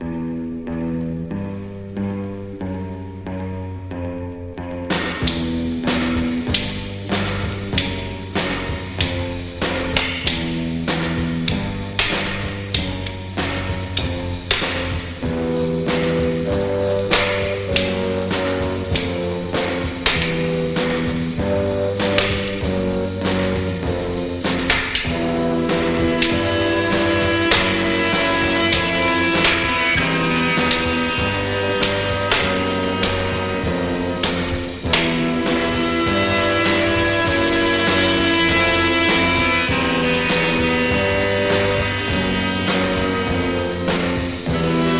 Rock’N’Roll, Garage Rock　USA　12inchレコード　33rpm　Mono